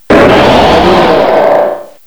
cry_not_mega_sceptile.aif